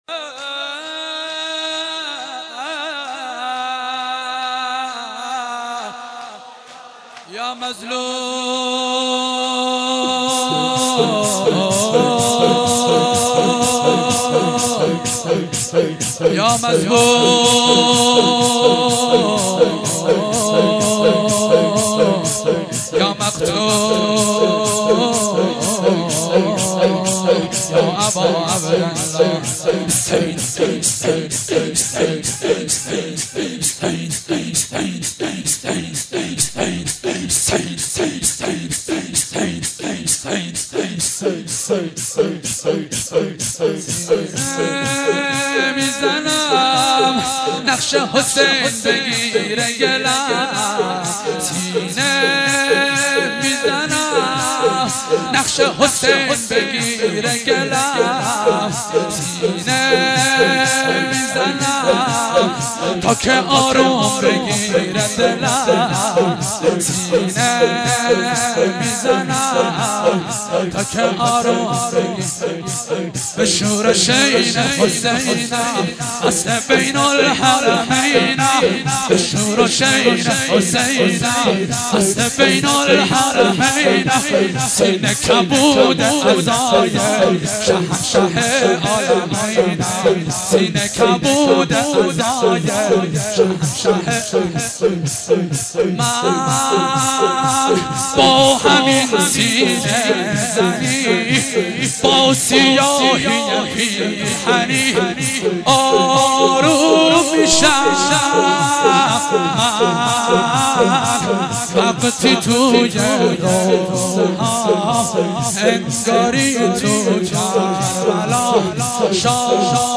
مراسم عزاداری شب شهادت حضرت فاطمه زهرا(س) با حضور صدها ساهپوش فاطمی در هیات قمر بنی هاشم شهر ساری برگزار شد.
فایل صوتی مداحی